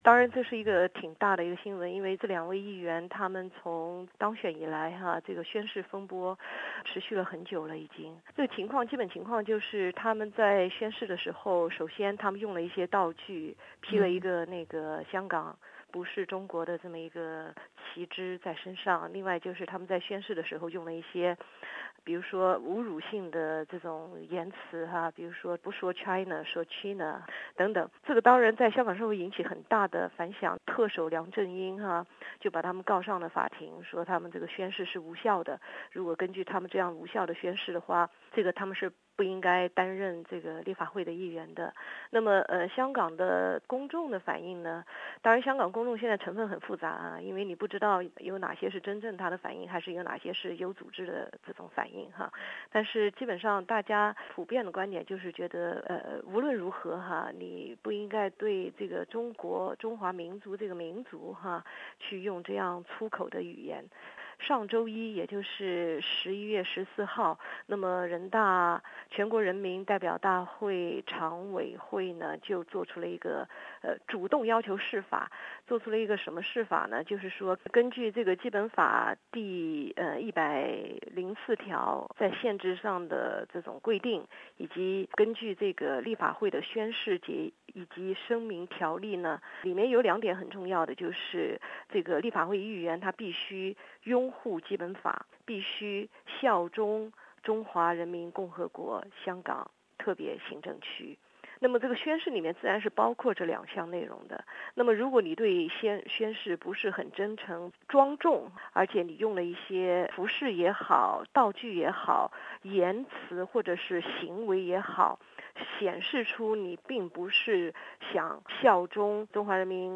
她首先简单回顾了这次香港立法会宣誓风波： （以上为嘉宾观点，不代表本台立场。）